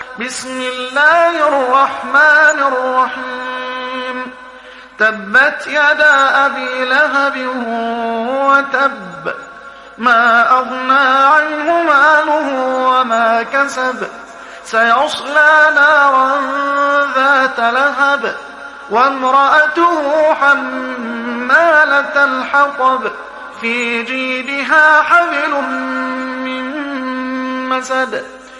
تحميل سورة المسد mp3 بصوت محمد حسان برواية حفص عن عاصم, تحميل استماع القرآن الكريم على الجوال mp3 كاملا بروابط مباشرة وسريعة